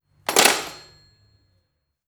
Colgar el auricular de un teléfono de los años 30